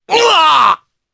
Oddy-Vox_Damage_jp_03.wav